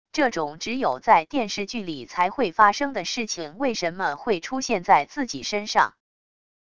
这种只有在电视剧里才会发生的事情为什么会出现在自己身上wav音频生成系统WAV Audio Player